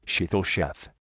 En-us-pronunciation-sheetal-sheth.ogg.mp3